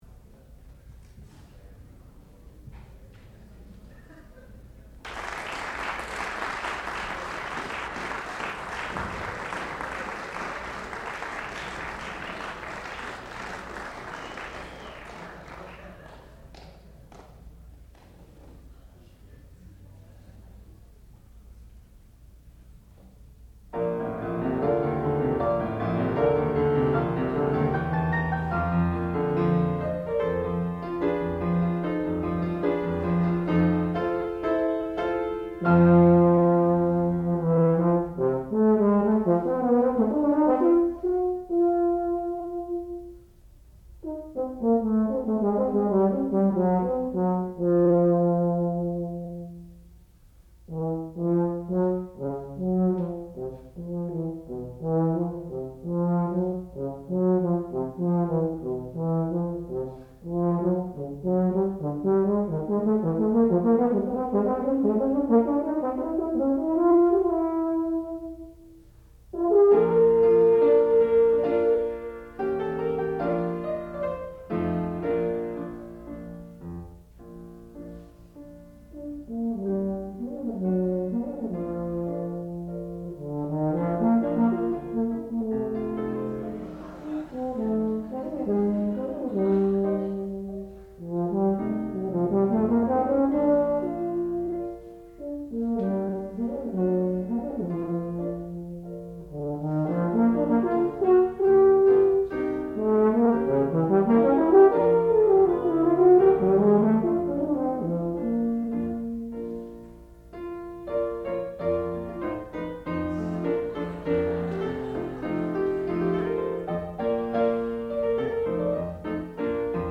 sound recording-musical
classical music
piano
tuba